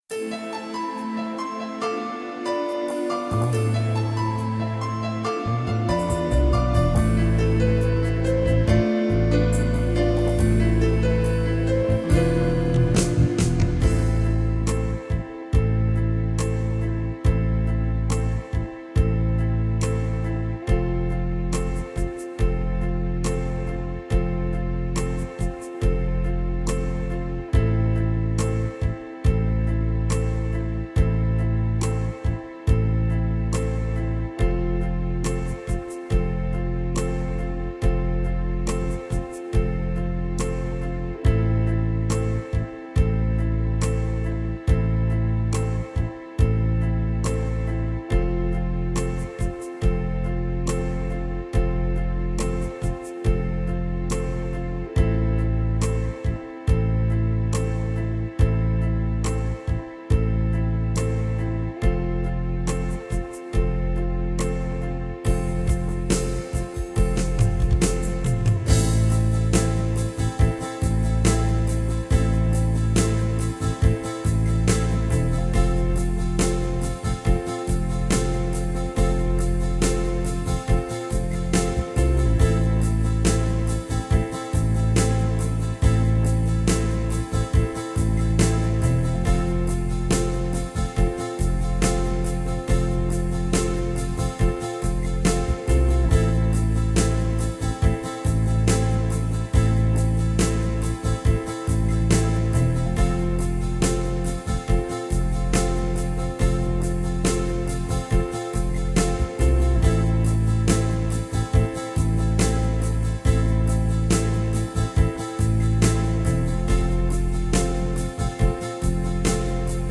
Track 2 - Am
• The 2nd track is soft, it is very generic and fits rock/pop ballad improvisation.
Style: Neoclassic, Pop, Ballad
Chord Progression: Am .. F
Soft_Jam_Track_in_Am___70_BPM.ogg